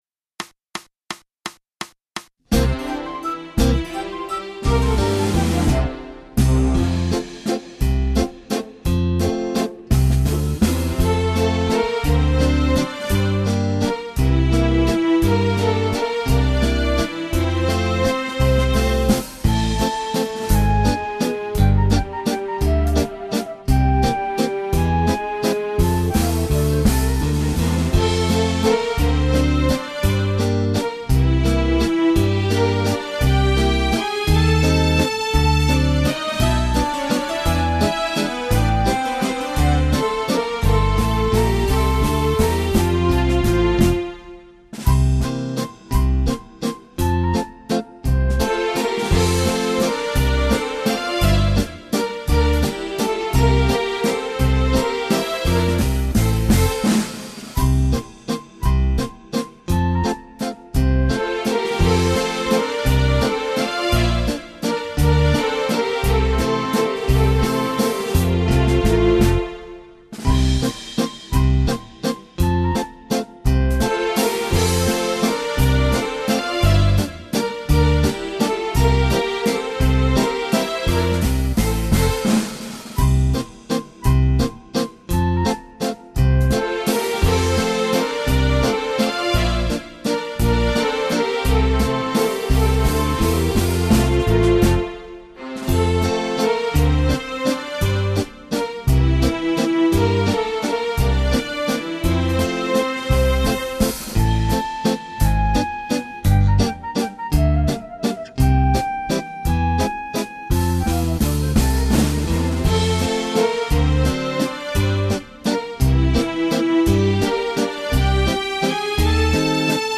Genere: Valzer viennese
Scarica la Base Mp3 (3,24 MB)